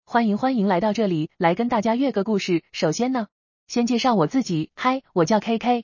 試聽看看吧：嗨，我是AI抖音女主播聲。
嗨，我叫KK-女.mp3